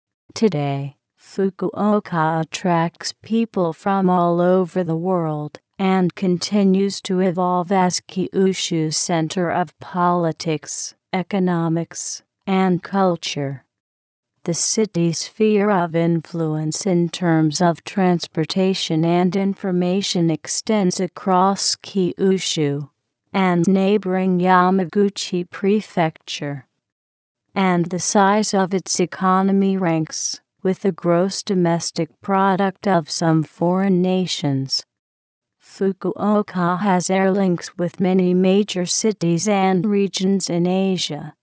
注：上表の音声データはTextAloudによるコンピュータ合成音です。